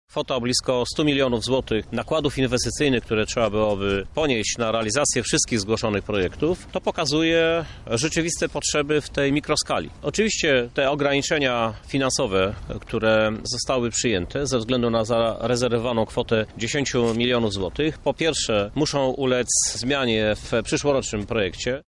O Budżecie Obywatelskim mówi Prezydent Miasta Krzysztof Żuk.